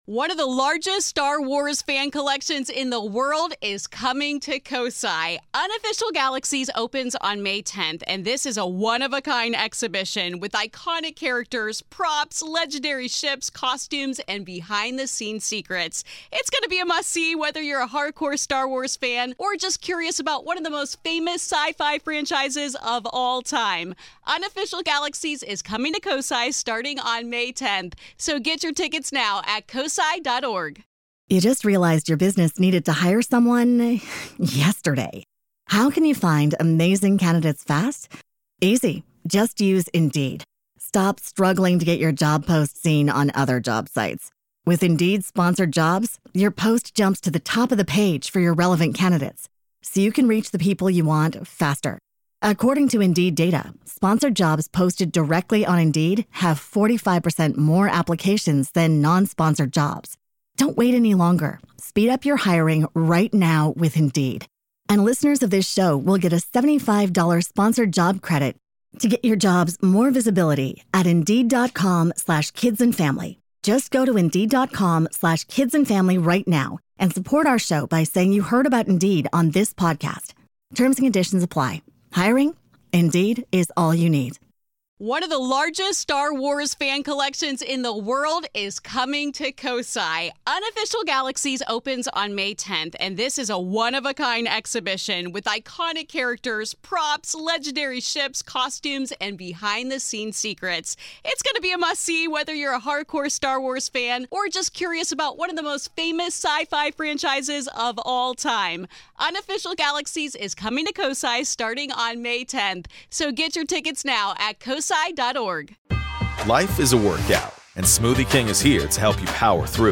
Today we have an in-depth conversation